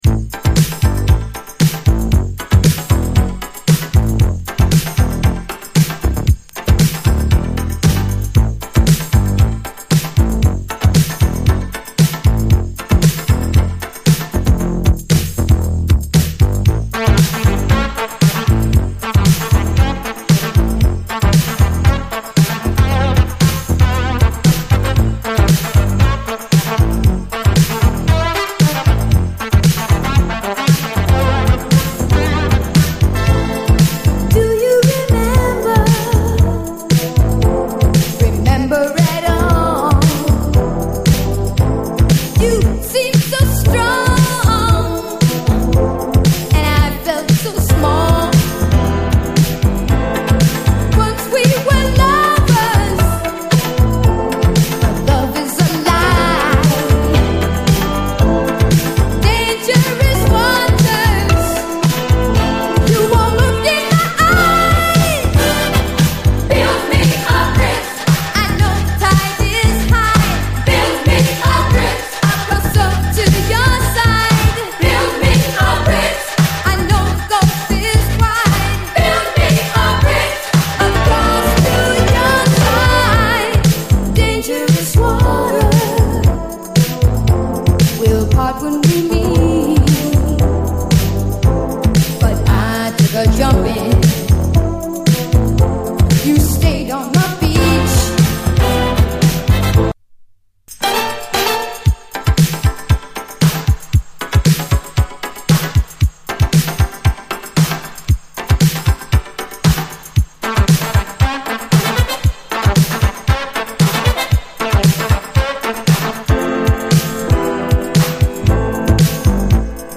全編異常にクオリティーの高いラヴァーズの連続で窒息しそうですが
えも言われぬ至福のグルーヴにKOされます！